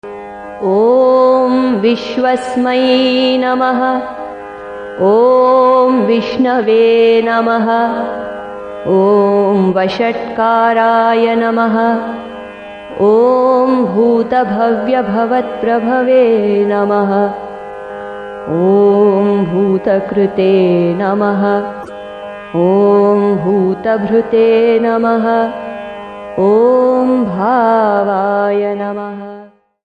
Melodious chantings